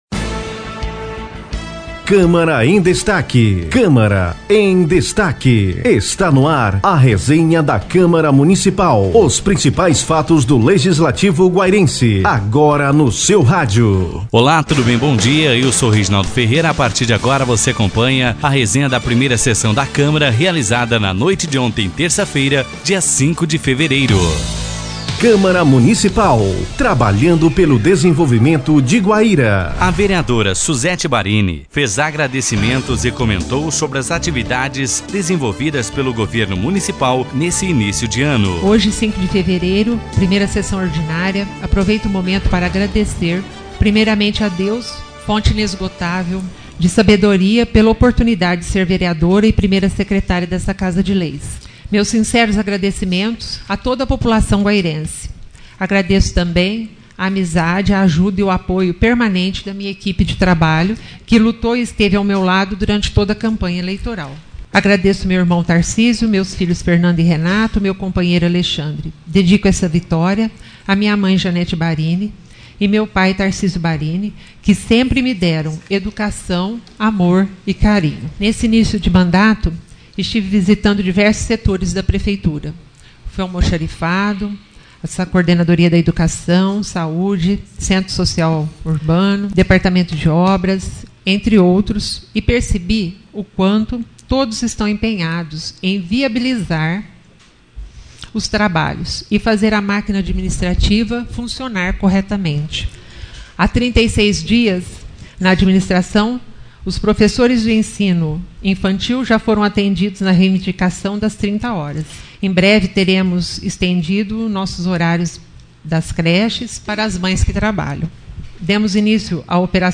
Tag: Resenha Resenha da Câmara Municipal de Guaíra, Sessão Ordinária realizada na última terça-feira (19).